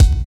112 KICK 2.wav